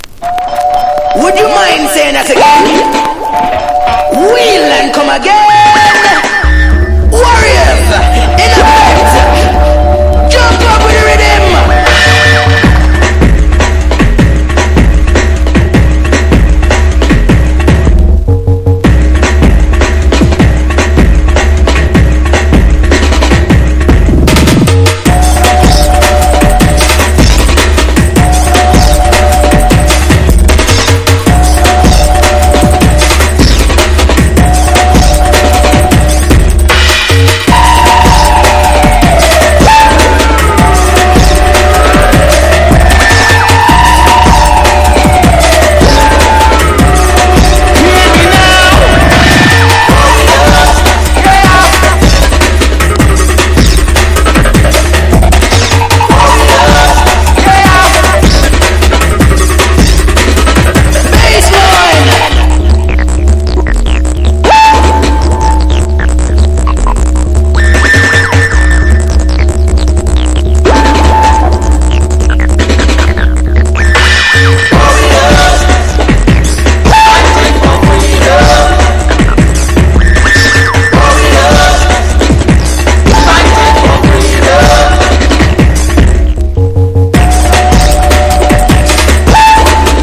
DUB / UK DUB / NEW ROOTS# DRUM N BASS / JUNGLE